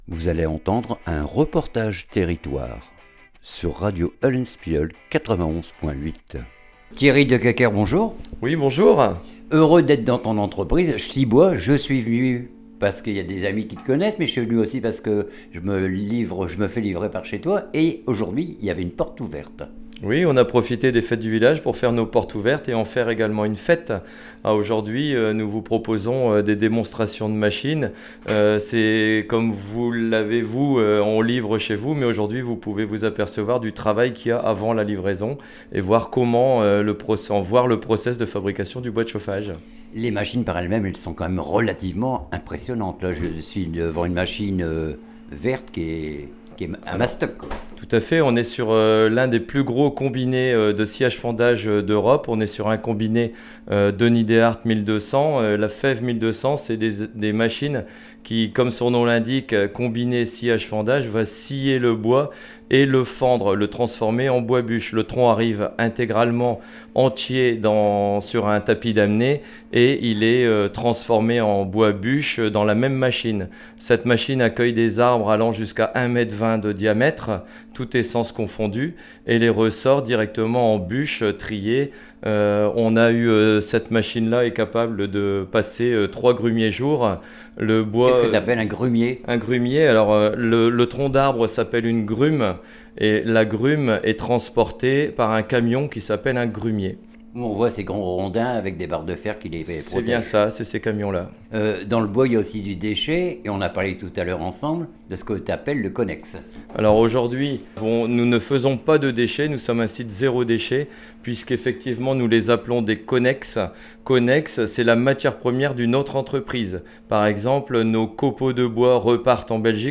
REPORTAGE TERRITOIRE CHTIBOIS HONDEGHEM